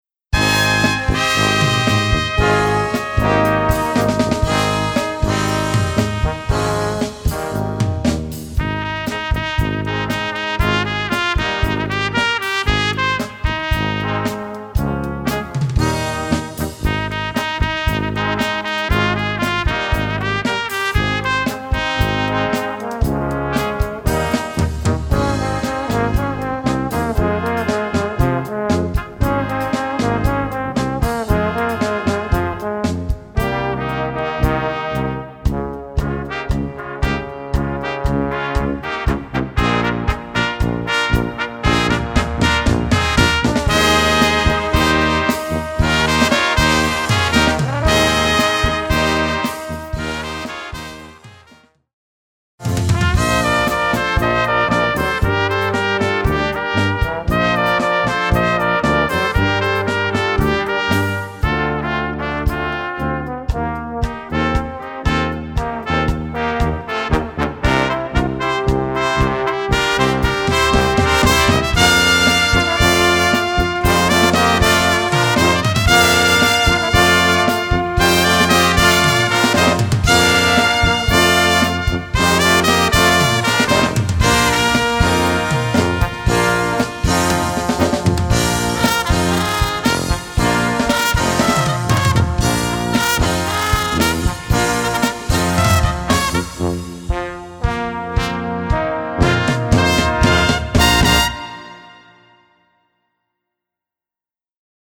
Gattung: Evergreen
Besetzung: Kleine Blasmusik-Besetzung
Stimmensatz Sextett: